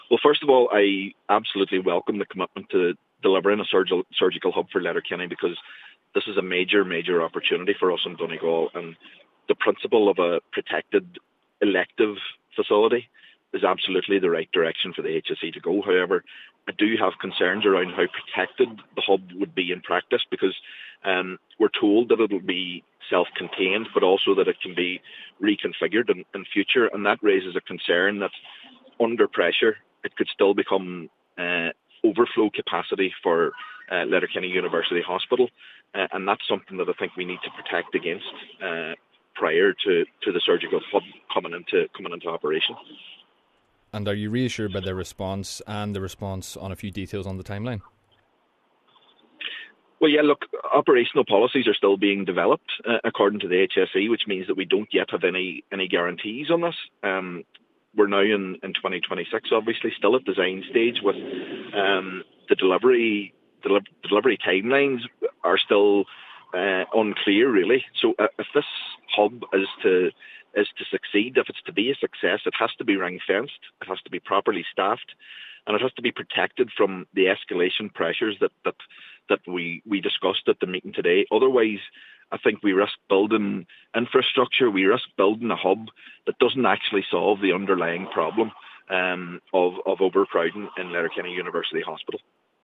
Cllr Doherty says it’s the right move in principal but still has concerns: